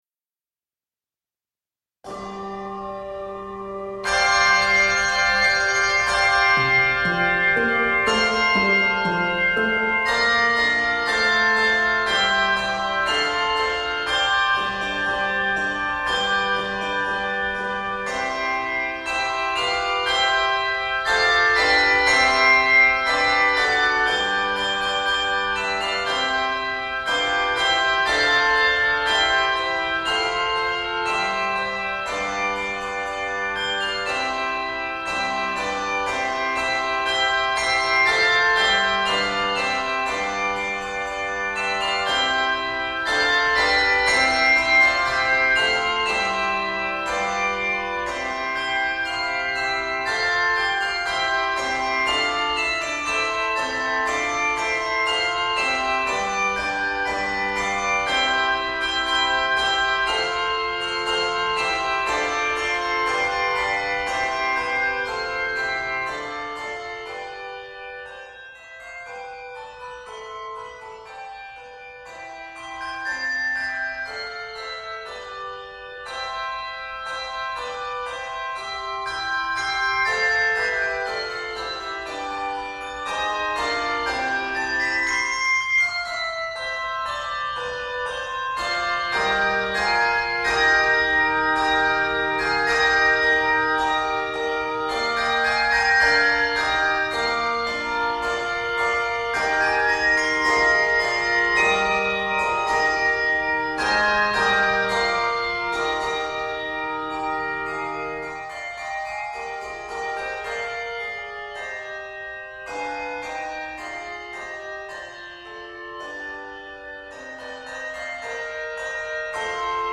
This robust Easter piece